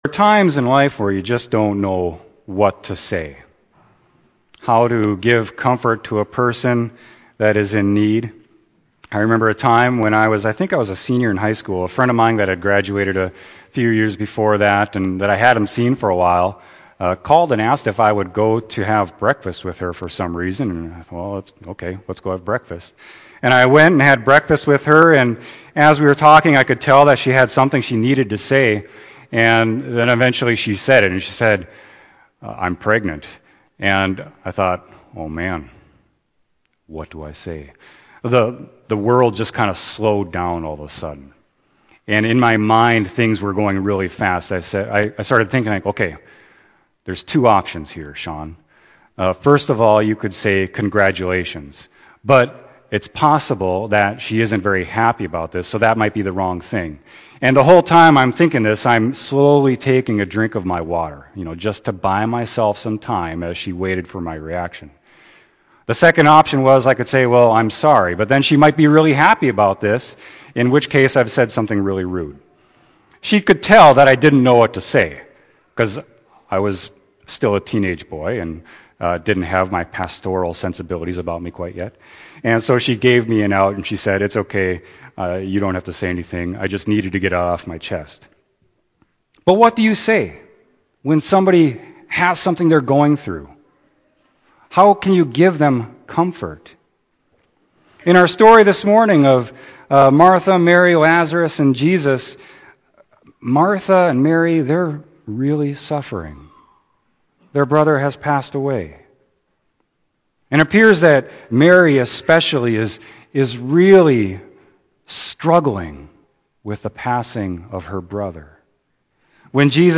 Scriptures: Ezekiel 37:1-14; Psalm 130; Romans 8:6-11; John 11:1-45 Sermon: John 11.1-45